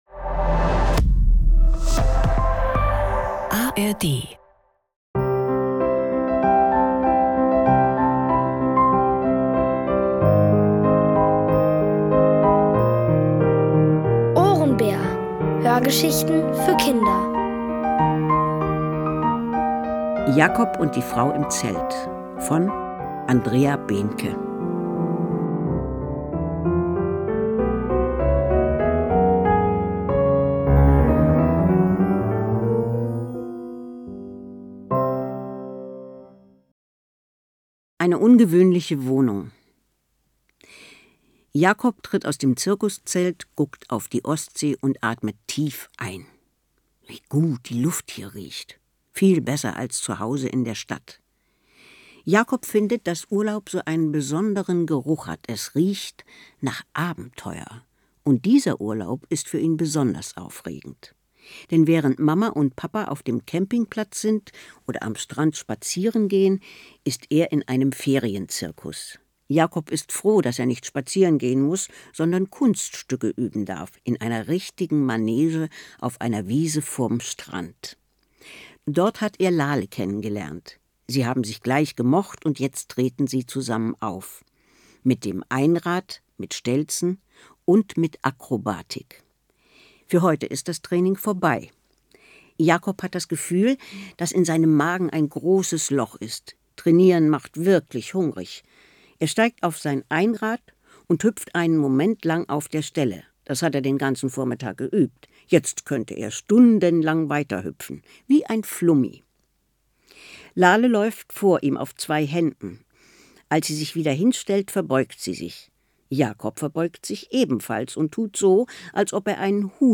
Jakob und die Frau im Zelt | Die komplette Hörgeschichte!